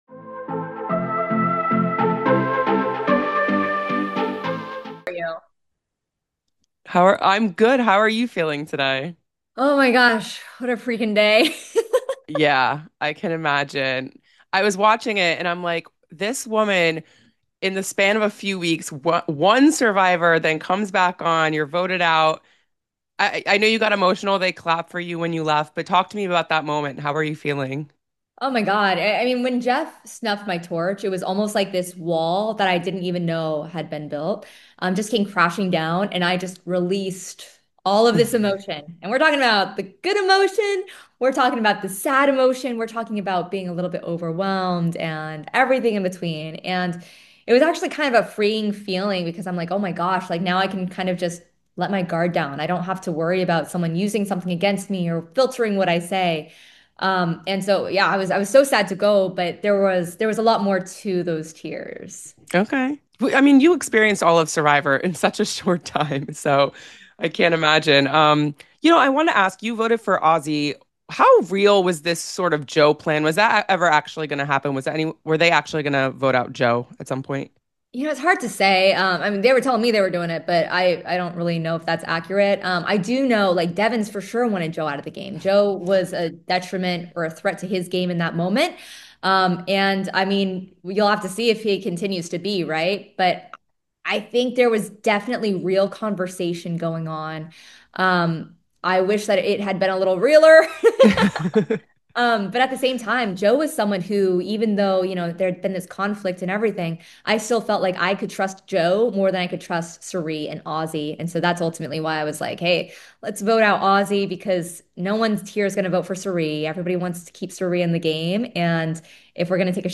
Survivor 50 Exit Interview: Third Player Out